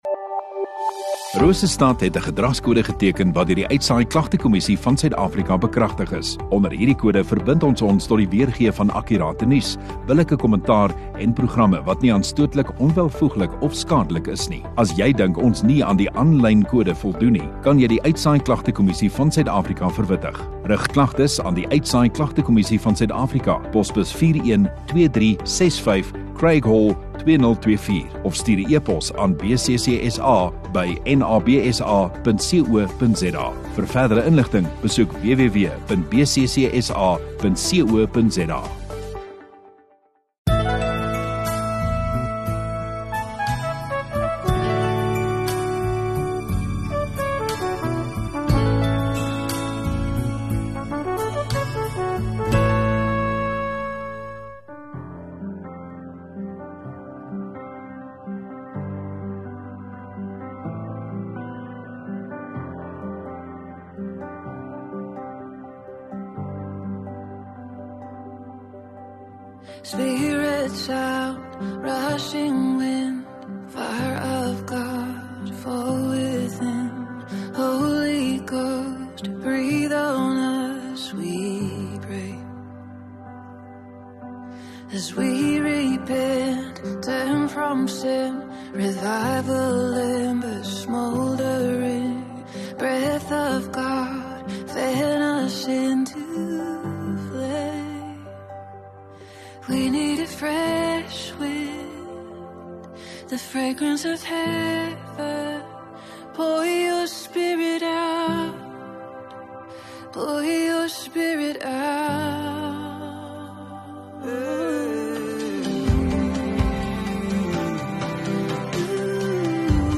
26 Apr Saterdag Oggenddiens